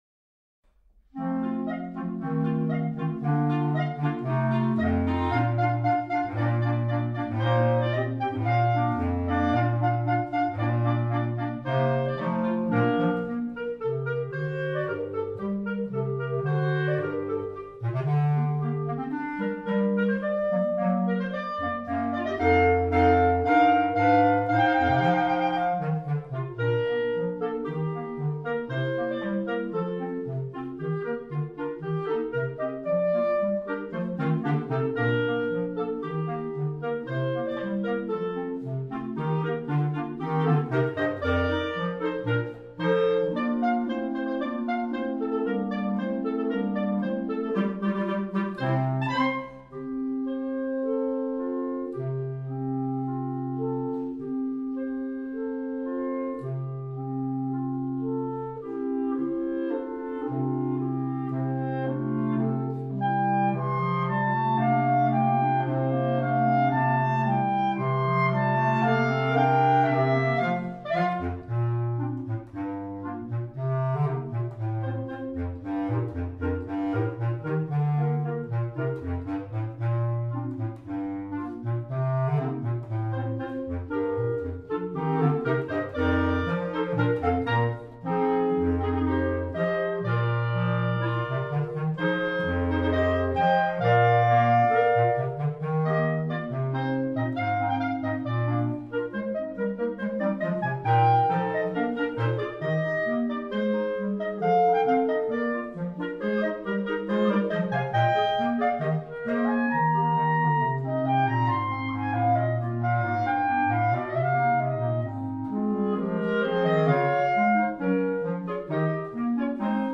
B♭ Clarinet 1 B♭ Clarinet 2 B♭ Clarinet 3 Bass Clarinet
单簧管四重奏
现由单簧管合奏呈现